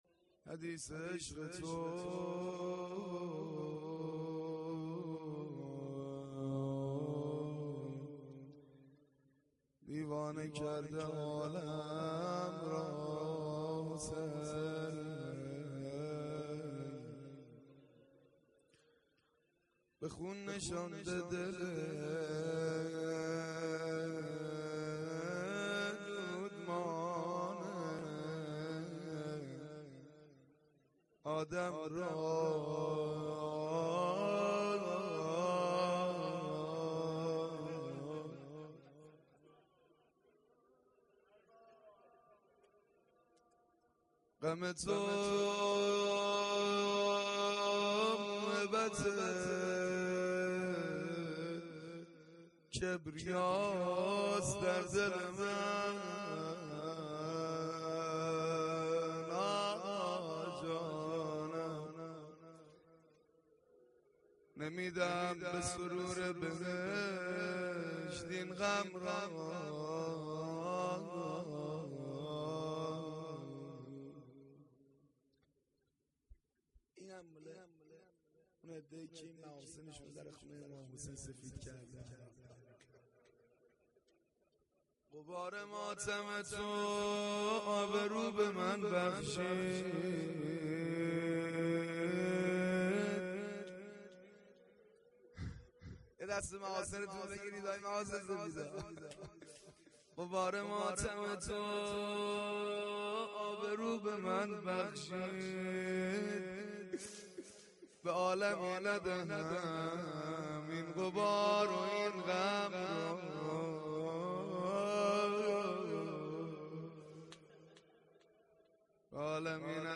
مداحی روضه شب ششم